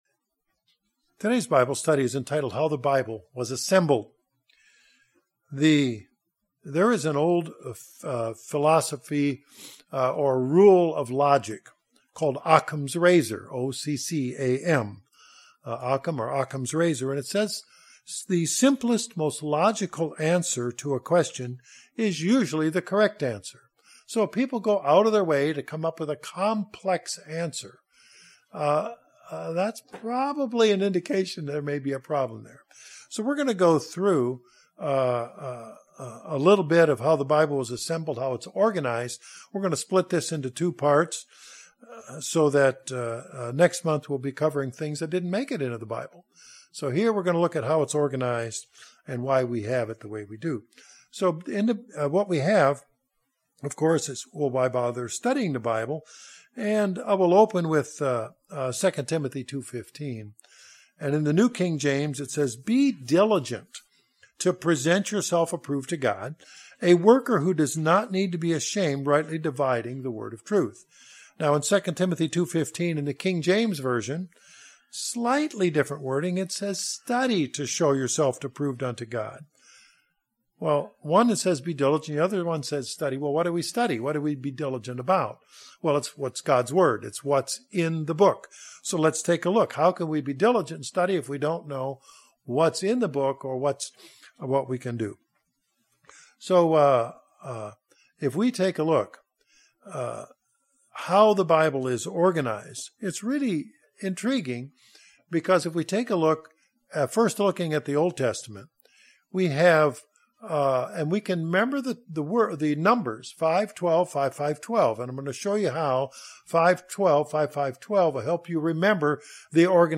Bible Study - How the Bible Was Assembled from UCG NWA
Given in Northwest Arkansas